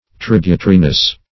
Tributariness \Trib"u*ta*ri*ness\, n. The quality or state of being tributary.